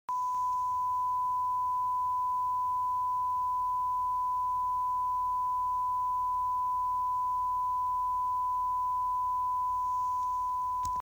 聴力検査サンプル音(SamplefortheHearingTest)
低音域（MP3：173KB）(Low-pitched)
1000hz.mp3